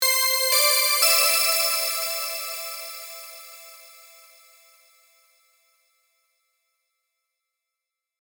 キラキラしたベル系の音です。